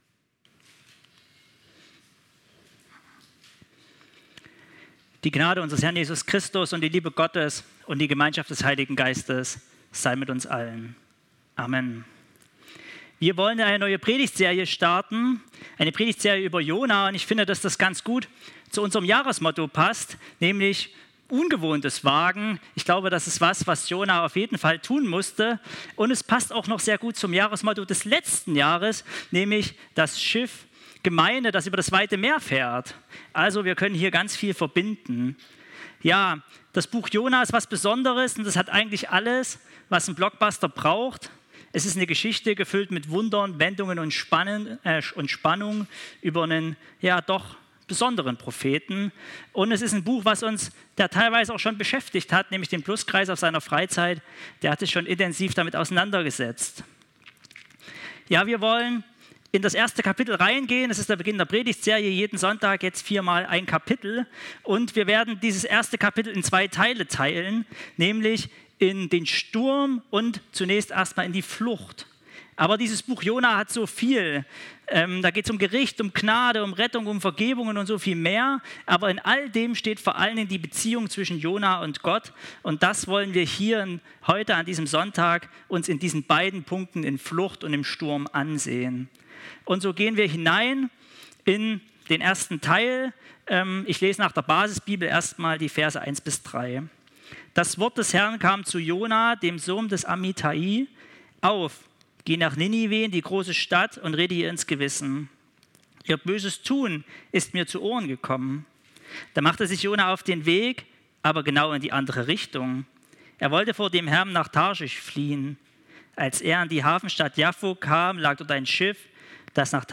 Teil 1 der Predigtreihe über Jona